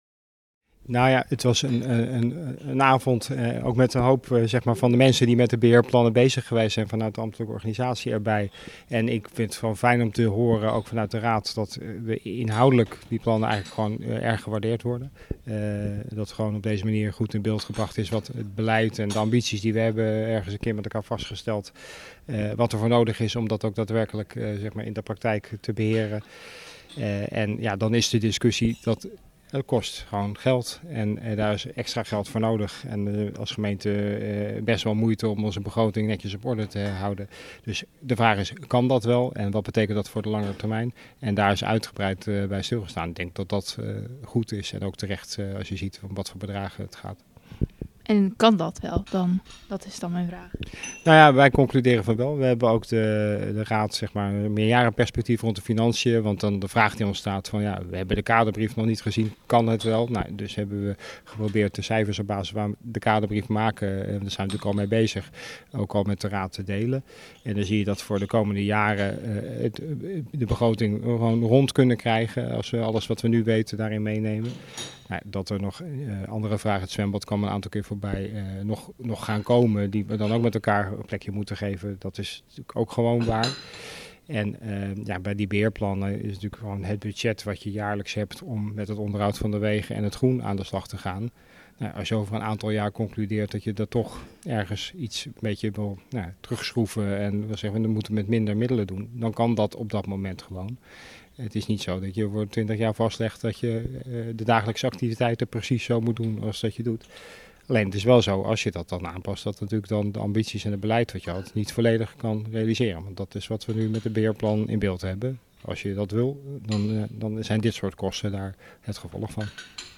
Wethouder Elfred Bus over de kosten rondom het onderhoud.